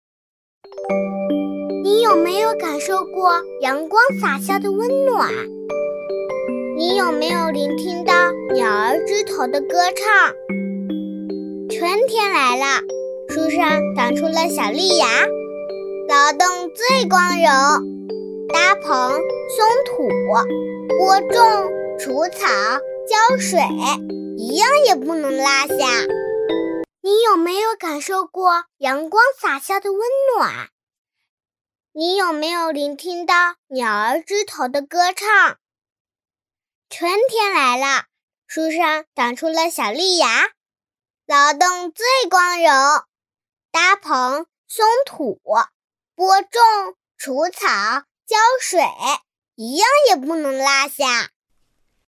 女B32-女真童 幼儿园
女B32-大气质感 可爱
女B32-女真童 幼儿园.mp3